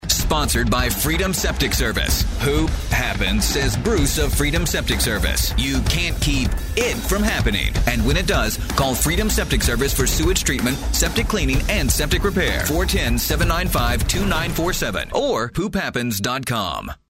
septic-radio-spot.mp3